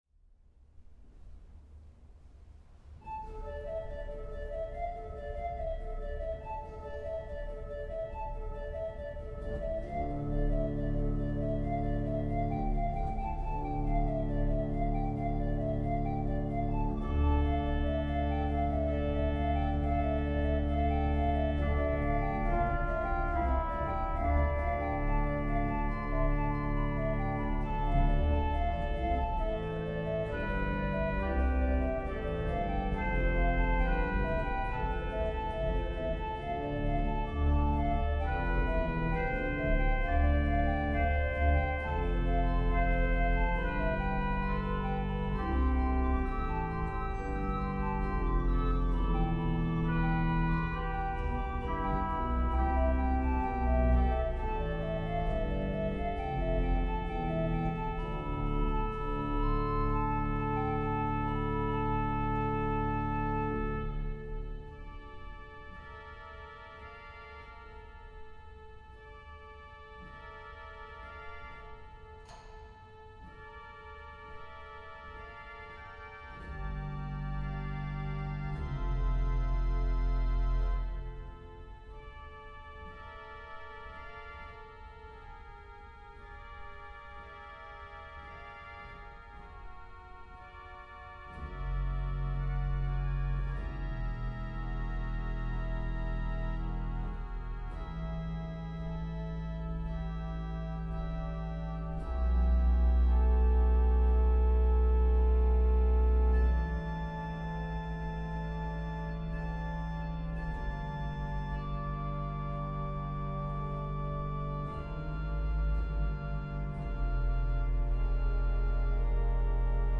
der heutige geistlich-musikalische Impuls ist eine Orgelimprovisation über Inhalt und Melodie eines der ältesten (um 1200) Gesänge aus unserem Gotteslob.
Der gregorianische Hymnus Jesu dulcis memoria ist uns als O lieber Jesu, denk ich dein bekannt und ist unter der Nummer 368 zu finden.
Orgel